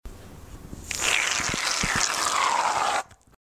Chorro de líquido saliendo a presión
Me gusta Descripción Grabación sonora que capta el sonido del chorro a presión del vaciado rápido del líquido de un recipiente por una boquilla pequeña. Sonidos cotidianos
Sonidos: Agua